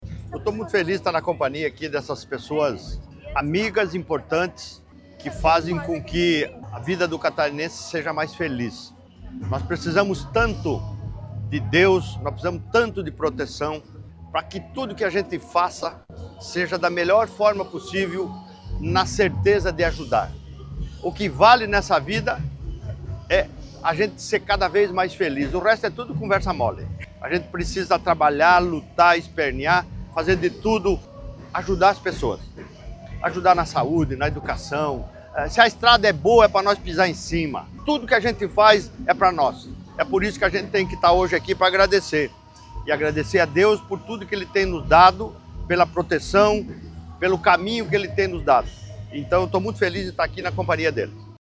O governador Jorginho Mello participou neste sábado, 15, em Florianópolis, da Marcha para Jesus.
SECOM-Sonora-governador-Marcha-para-Jesus.mp3